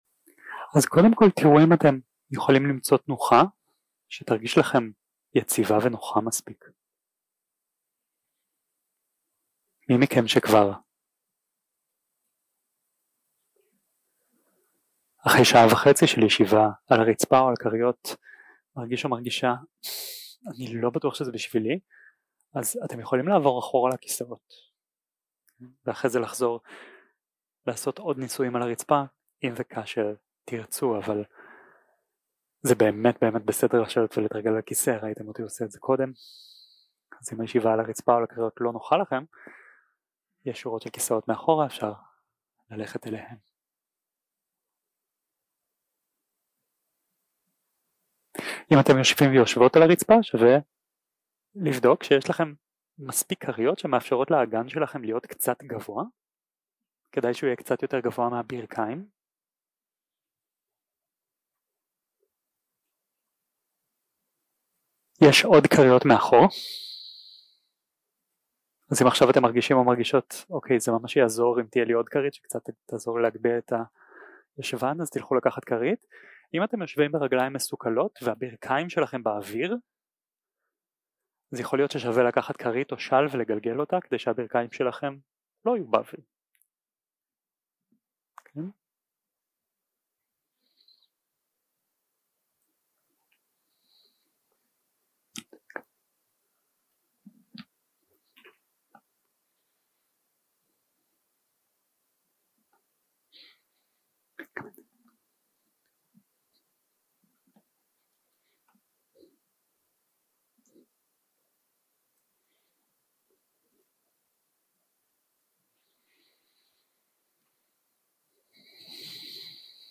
יום 1 – הקלטה 1 – ערב – מדיטציה מונחית
Dharma type: Guided meditation